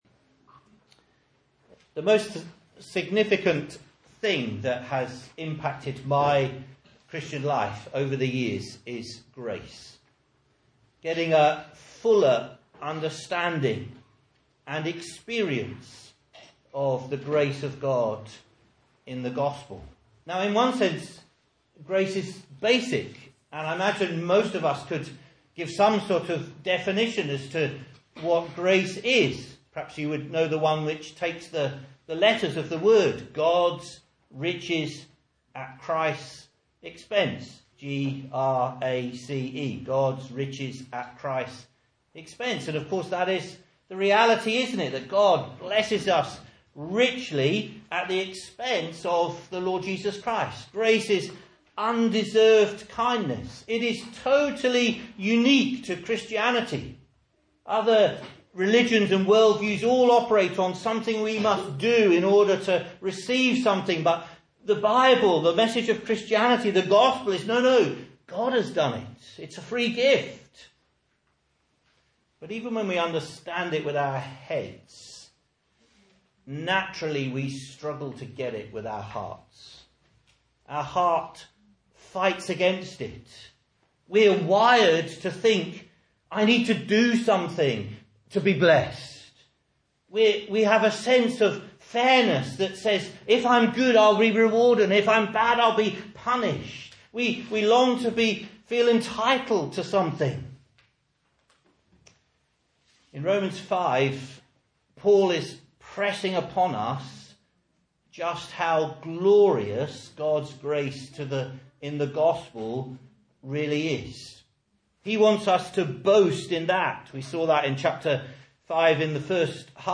Message Scripture: Romans 5:12-26 | Listen